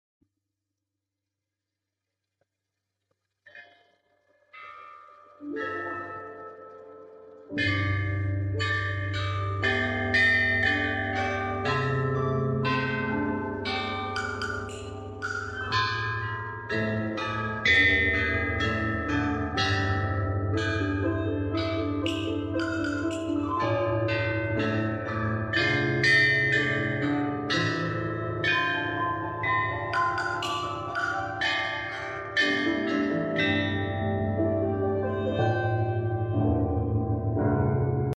Bianzhong percussion music originating sound effects free download